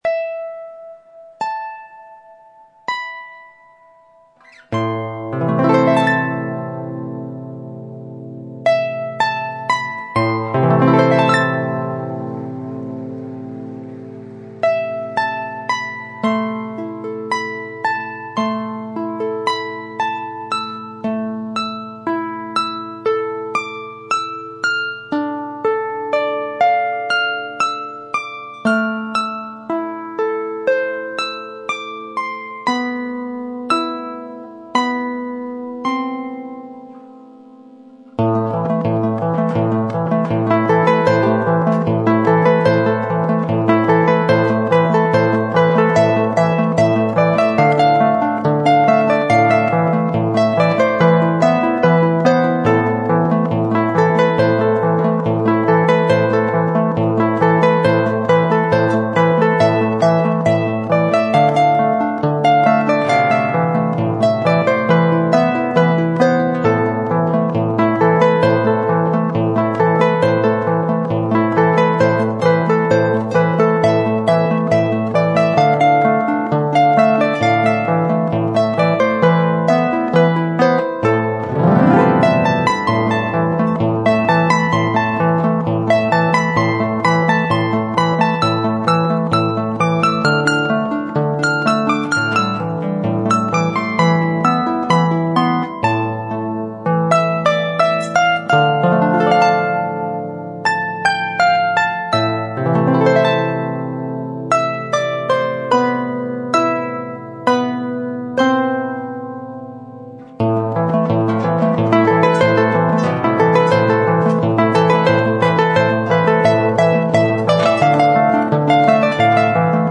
Harp